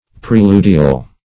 Preludial \Pre*lud"i*al\, a.